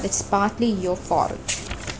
Here we host our database "IUEC (IIITD Urban Environment Context) database" which contains distress (scream and cry sounds) and sounds of 6 environmental contexts collected from mobile phones, movies and Internet.
Conversations     sample1.wav sample2.wav